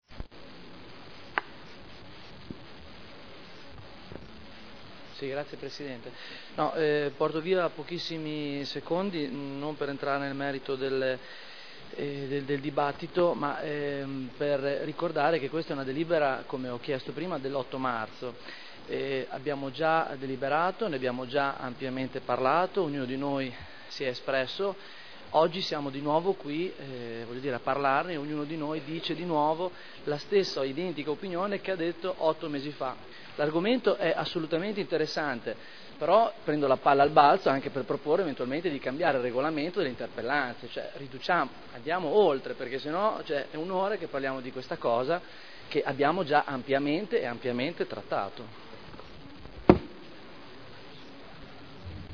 Stefano Barberini — Sito Audio Consiglio Comunale
Seduta del 17/01/2011.
Discussione